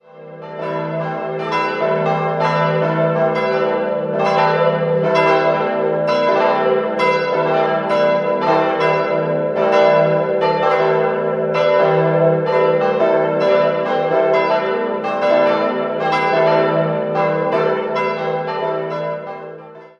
4-stimmiges ausgefülltes E-Moll-Geläut: e'-g'-a'-h'